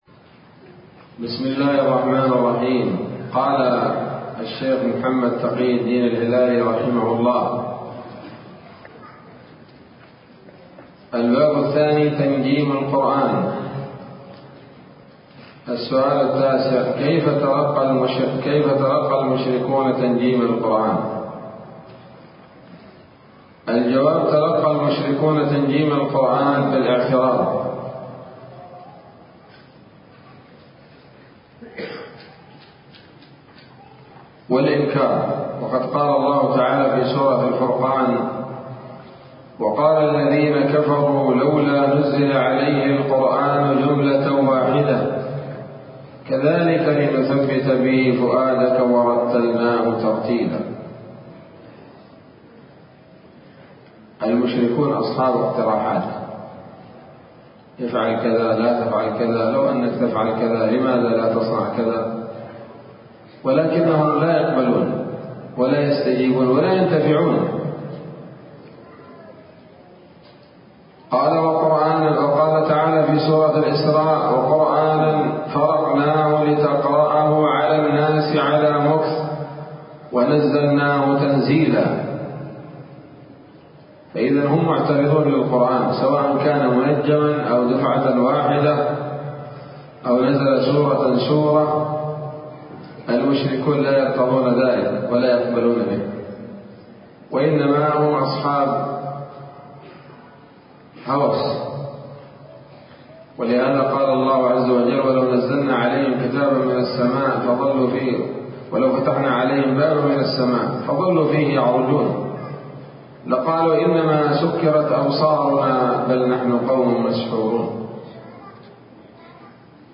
الدرس الخامس من كتاب نبذة من علوم القرآن لـ محمد تقي الدين الهلالي رحمه الله